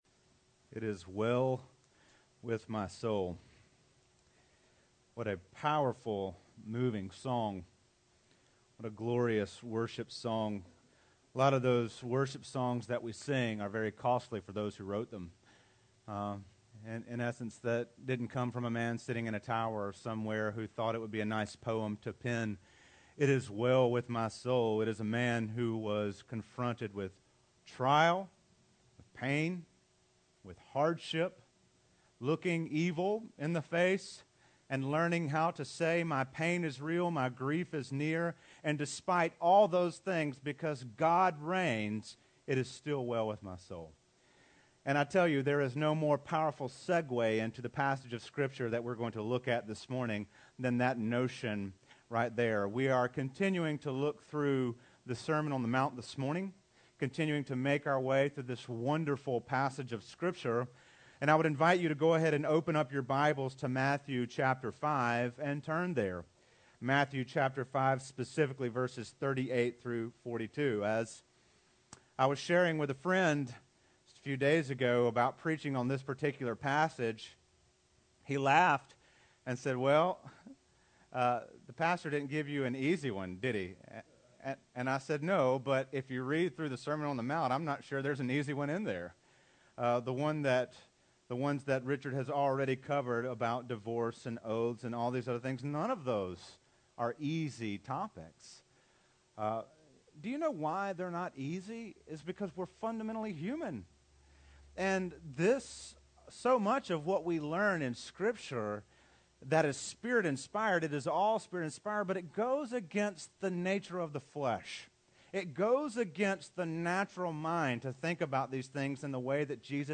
Series: Jesus' Sermon on the Mount